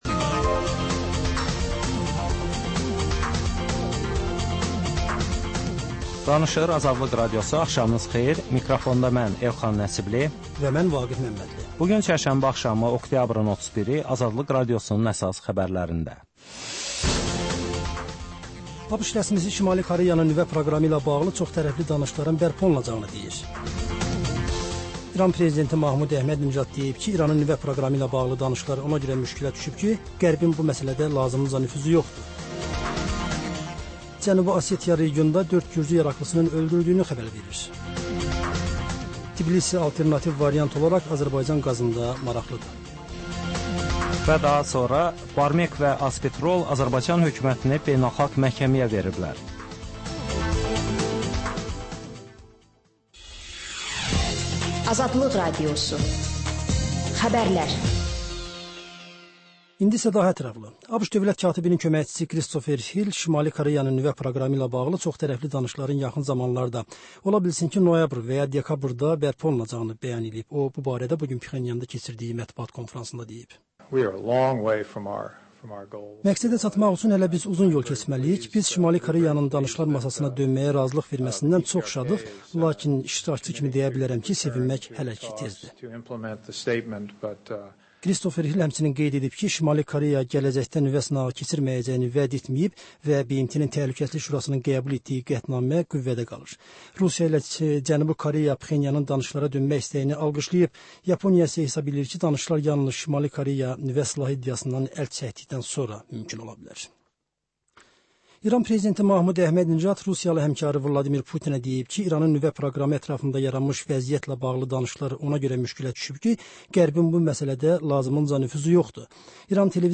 Xəbərlər, reportajlar, müsahibələr. Hadisələrin müzakirəsi, təhlillər, xüsusi reportajlar.